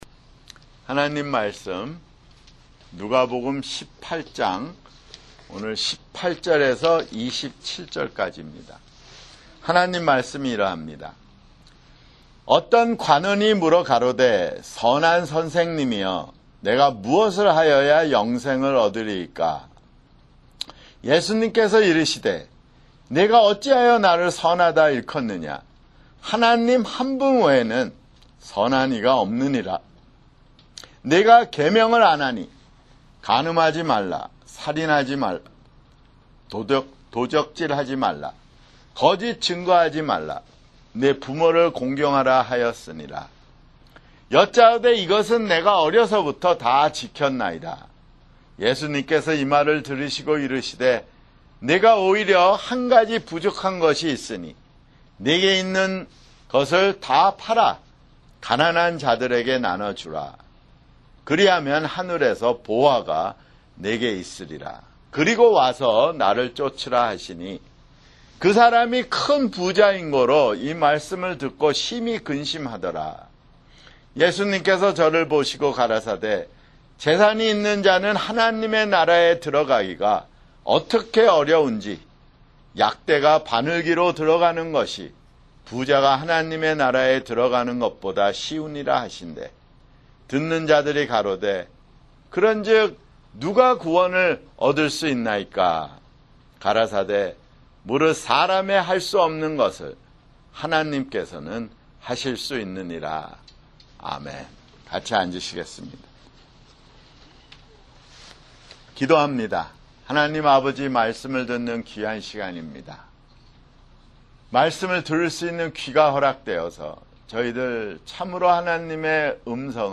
[주일설교] 누가복음 (120)